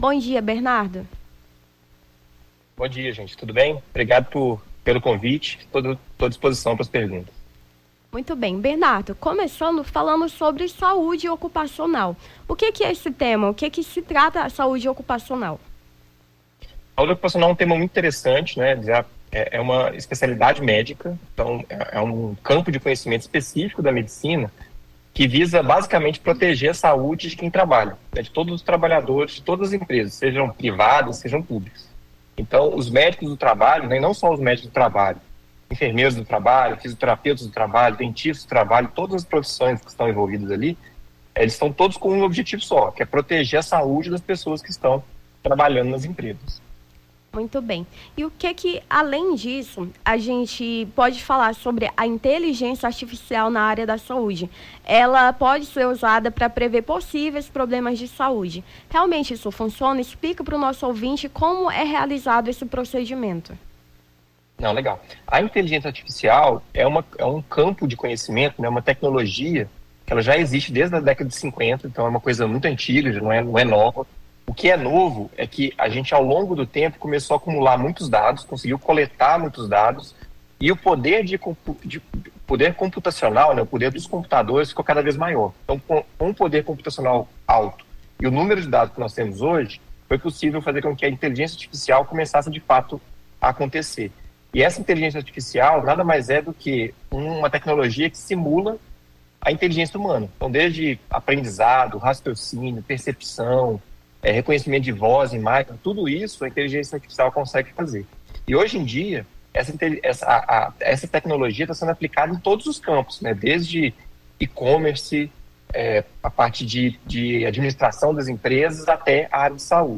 Nome do Artista - CENSURA - ENTREVISTA (DIA NACIONAL DA SAUDE) 04-08-23.mp3